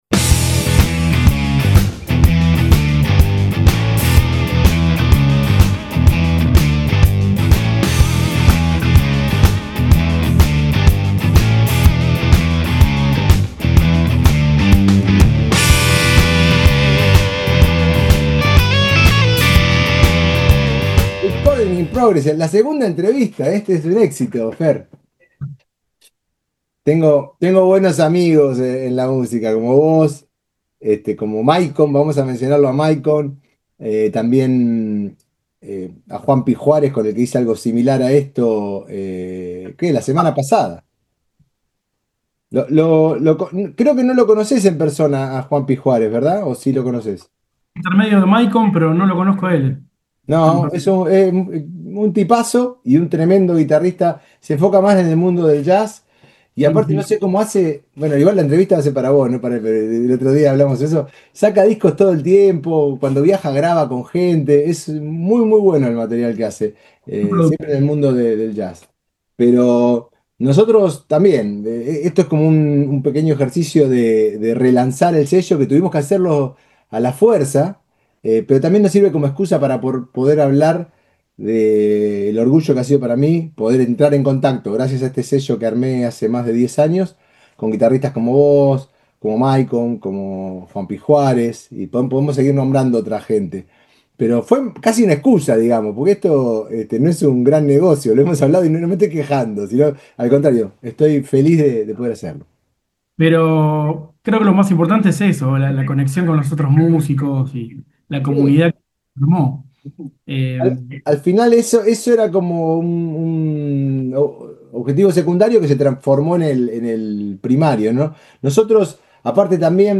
A lo largo de la charla